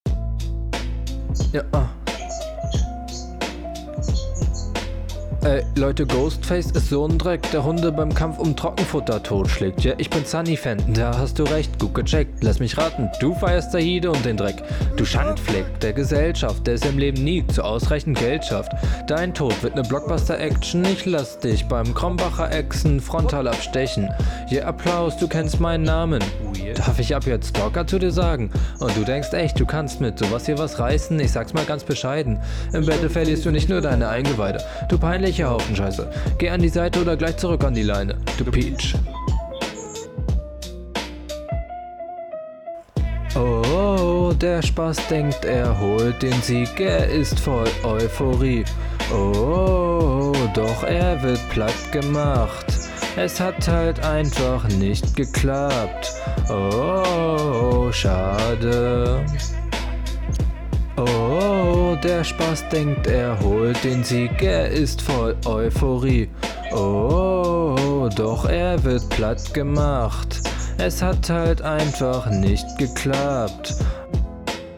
Text ist eigentlich gar nicht mal so schlecht ist aber leider sehr offbeat meiner Meinung …